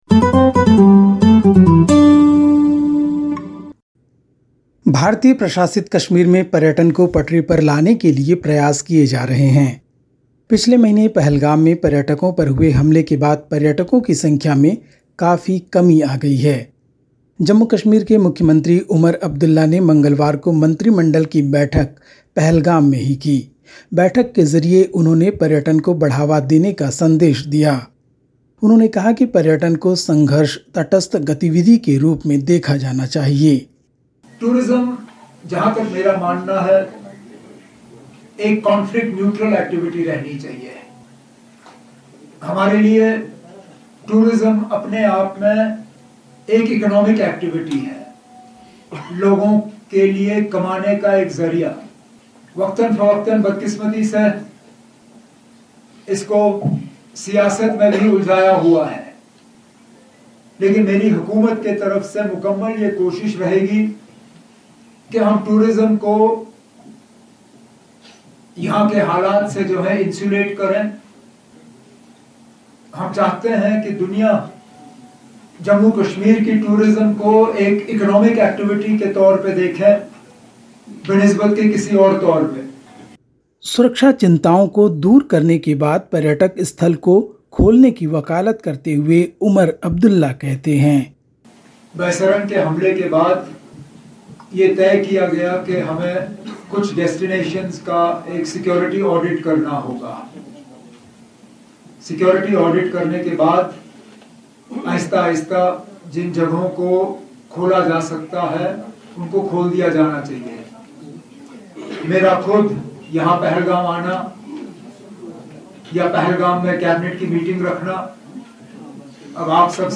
Listen to the latest SBS Hindi news from India. 28/05/2025